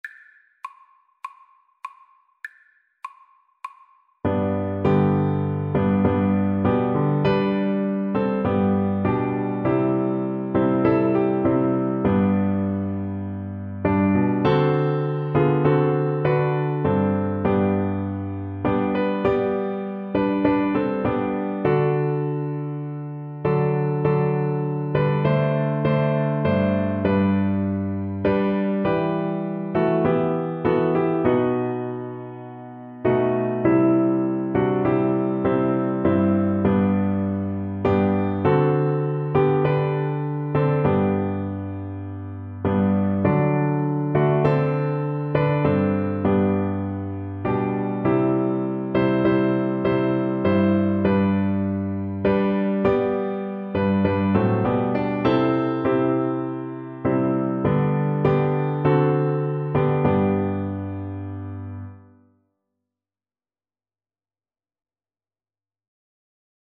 Tin Whistle (Penny Whistle) version
Christian Christian Tin Whistle
4/4 (View more 4/4 Music)
Classical (View more Classical Tin Whistle Music)